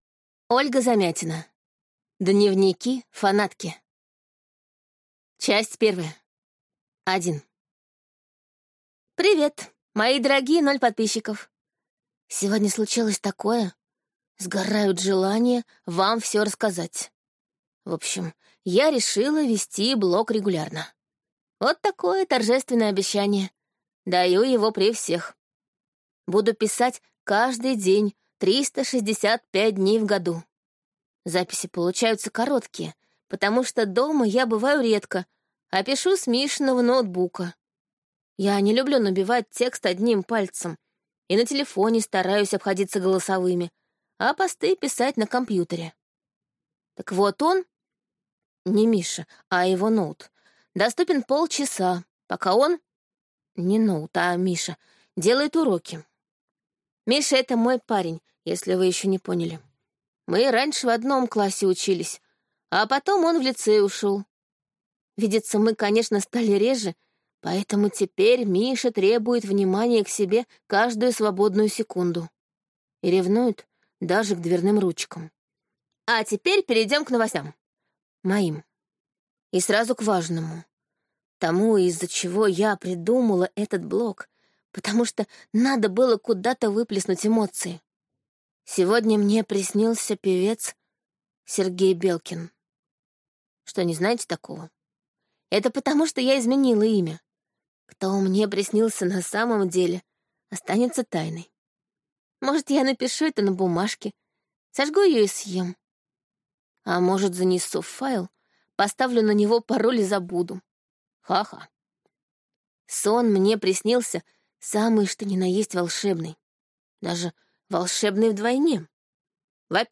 Аудиокнига Дневники фанатки | Библиотека аудиокниг